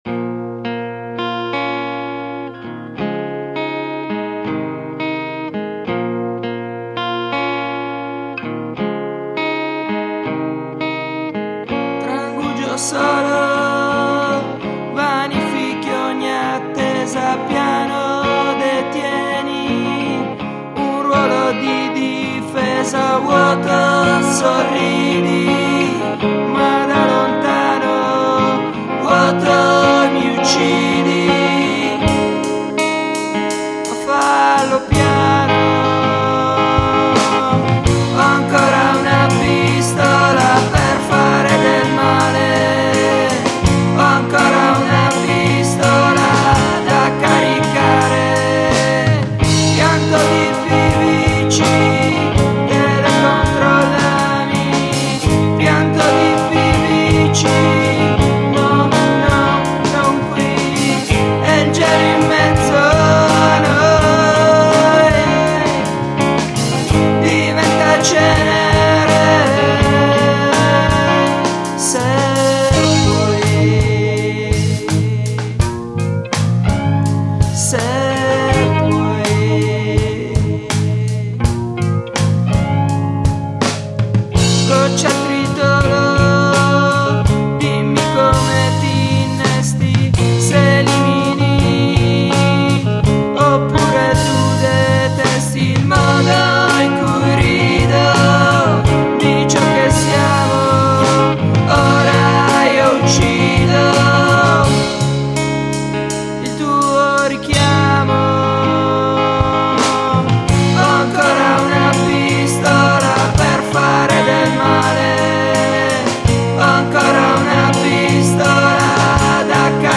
MUSIC>alternative rock